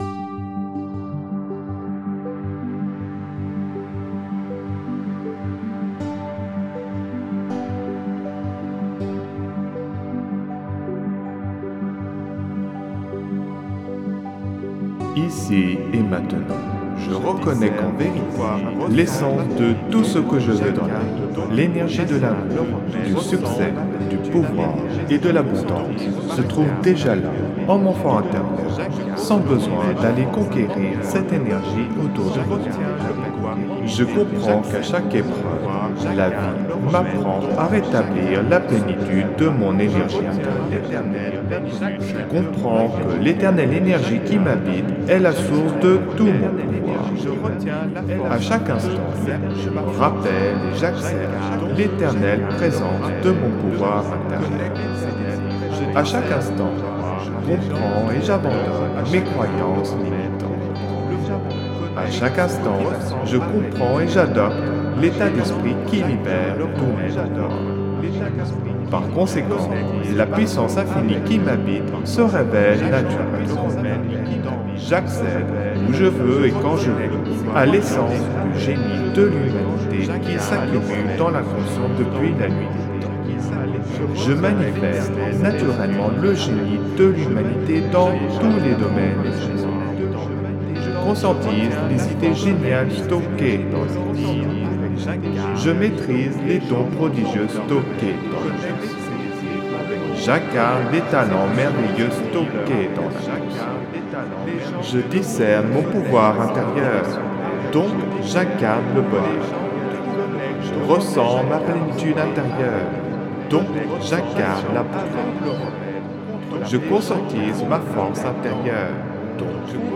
(Version ÉCHO-GUIDÉE)
Alliage ingénieux de sons et fréquences curatives, très bénéfiques pour le cerveau.
Pures ondes gamma intenses 97,66 Hz de qualité supérieure. Puissant effet 3D subliminal écho-guidé.